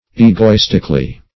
egoistically - definition of egoistically - synonyms, pronunciation, spelling from Free Dictionary Search Result for " egoistically" : The Collaborative International Dictionary of English v.0.48: Egoistically \E`go*is"tic*al*ly\, adv.
egoistically.mp3